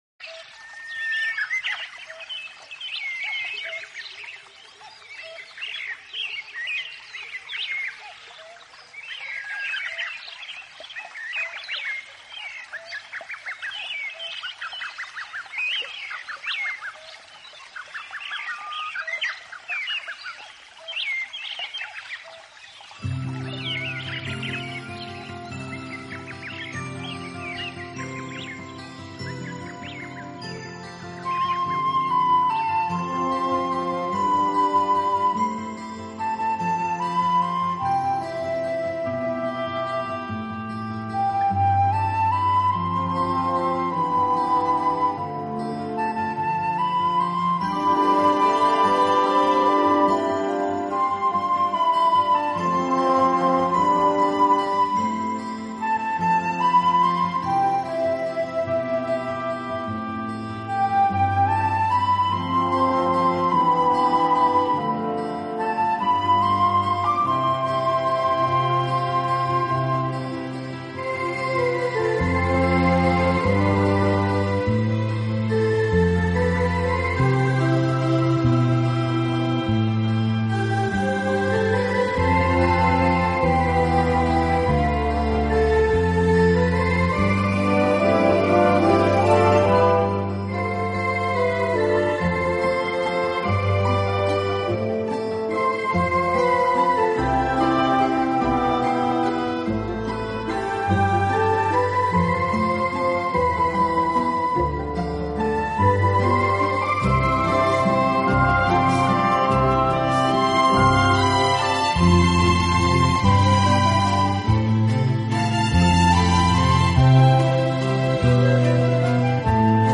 每一声虫鸣，流水都是从大自然中记录的，他们为了采集自然的
器配置，使每首曲子都呈现出清新的自然气息。